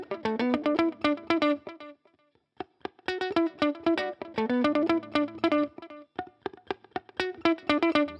11 Pickin Guitar PT4.wav